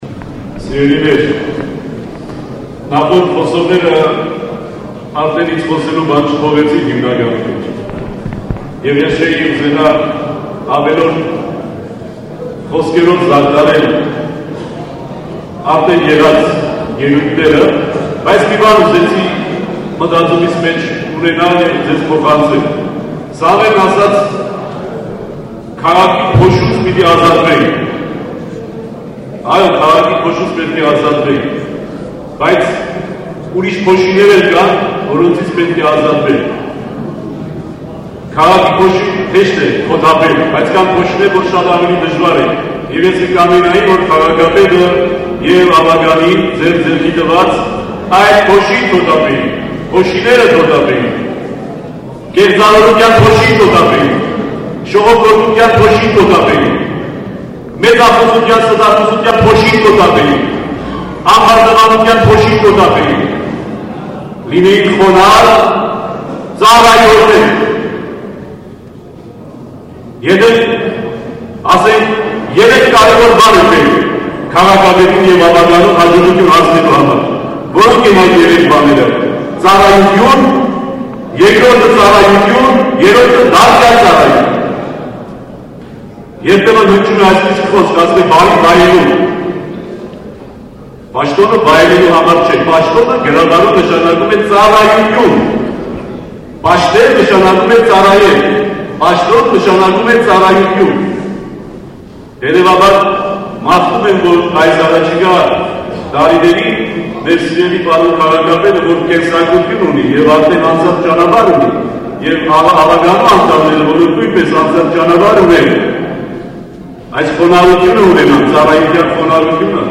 Սրբազանի խոսքն ամբողջությամբ՝ լսեք այստեղ: